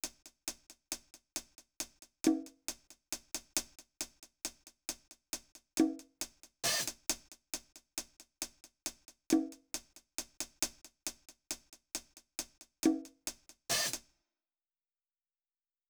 Cardiak_HiHat_Loop_2_136bpm.wav